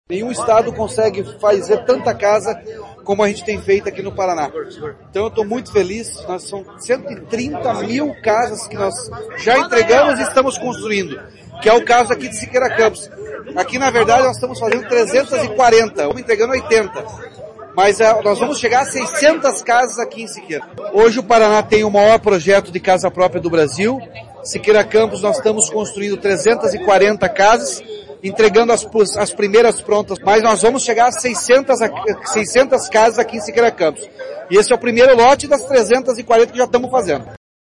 Sonora do governador Ratinho Júnior sobre as casas de Siqueira Campos